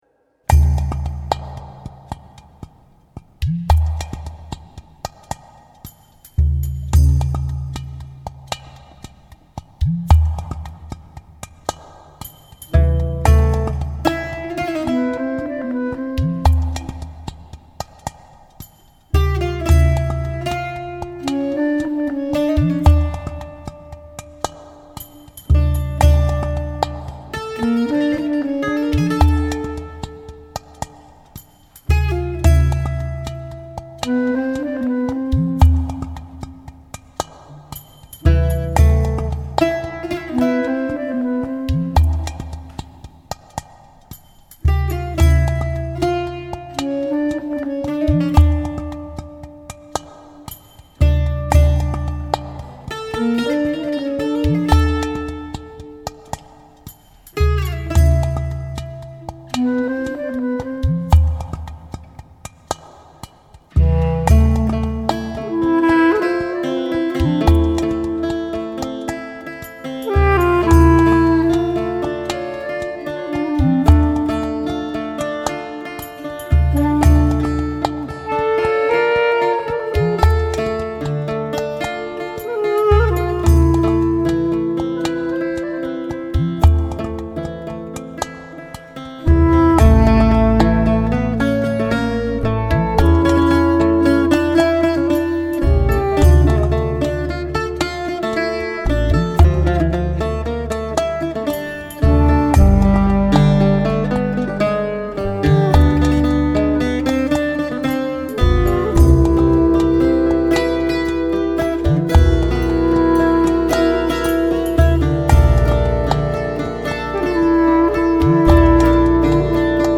錄音呈現出一貫的真實通透樂器質感與清晰無比的空間感與定位感，不同的錄音現場的不同殘響，所造成的不同質感也都如實再現
薩克斯風、單簧管及其它樂器
吉他及其它樂器
手風琴、鋼琴及其它樂器
貝斯、打擊樂器及其它樂器